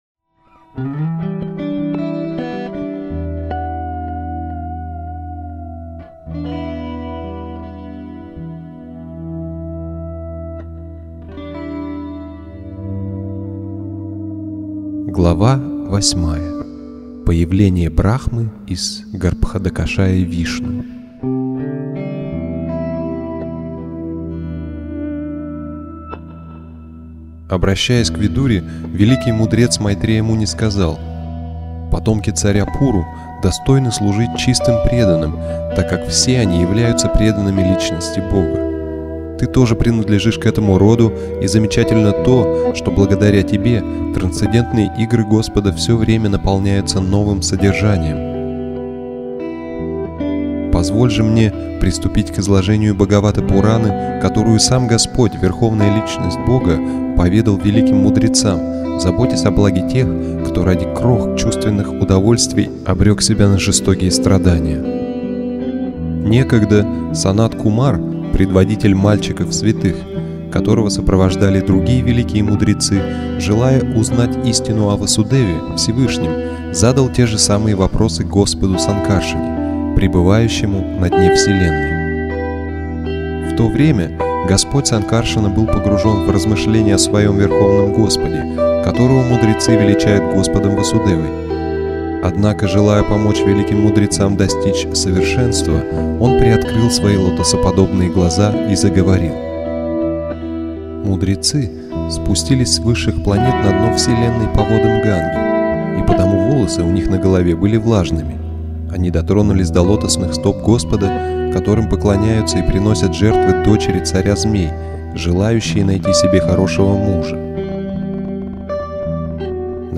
Аудиокнига "Шримад Бхагаватам.